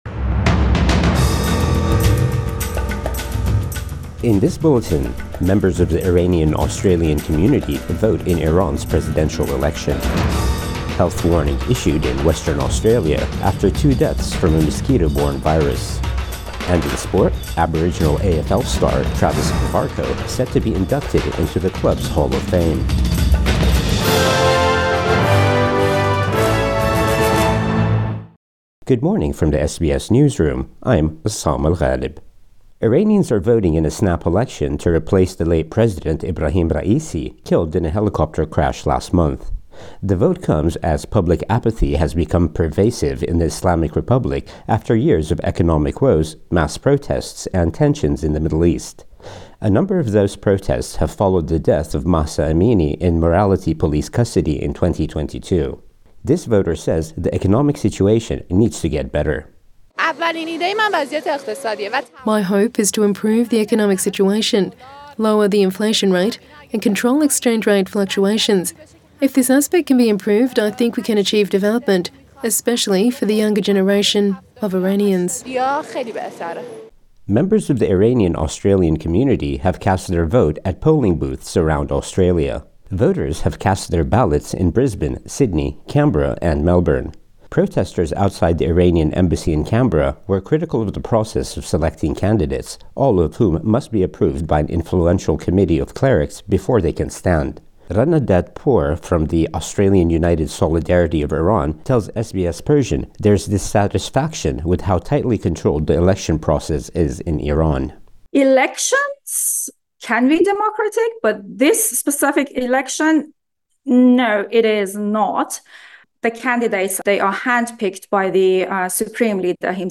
Morning News Bulletin 29 June 2024